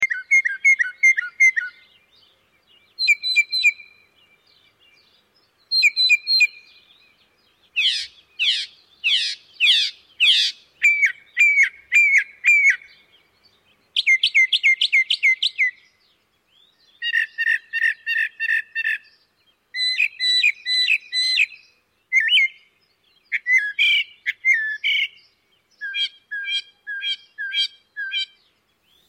Immediately, I was certain of the vocalist’s identity and his predicament as well: a young male Northern Mockingbird feeling lonely, or maybe just randy.
Unpaired males keep singing, almost to the point of obsession, belting out ballads and looking for love until late in the season, and late into the night.
northern-mockingbird.mp3